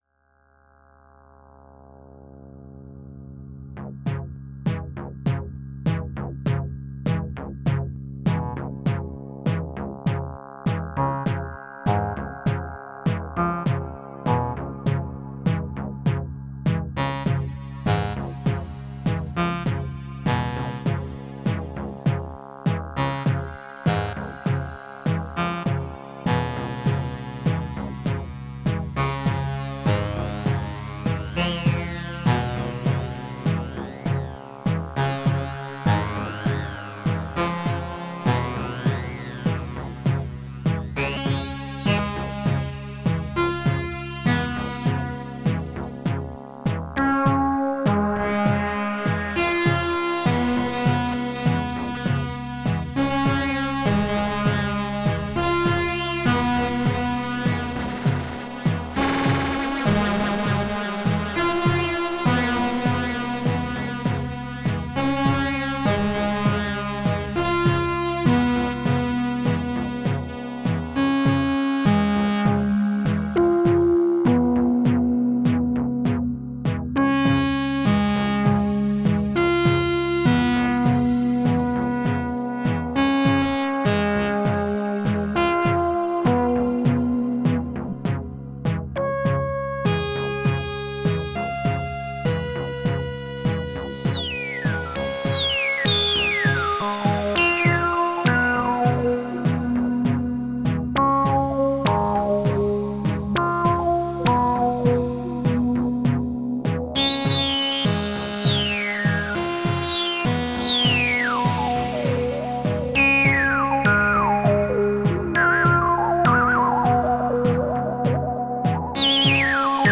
Звучит зараза искушающе!
одновременно и звонкий и скрипучий и зудящий и певучий и злой и мягкий
забыл ревер отключить - пусть так